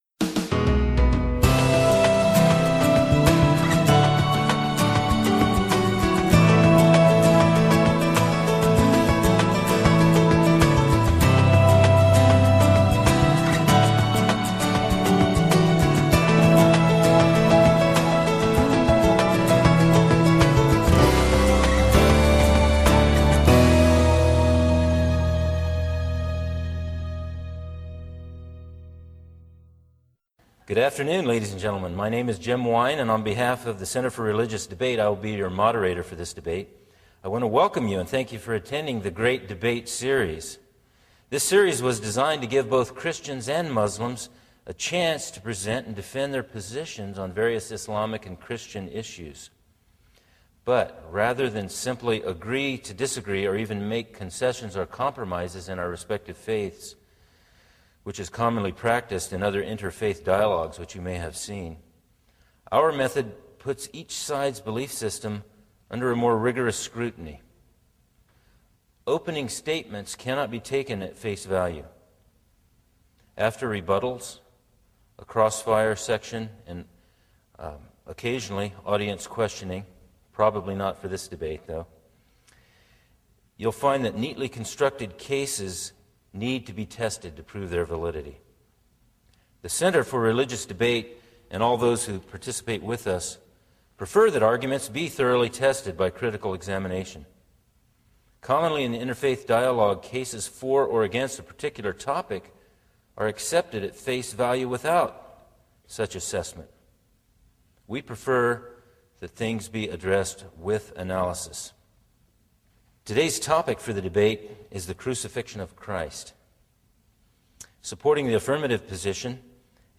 debate.mp3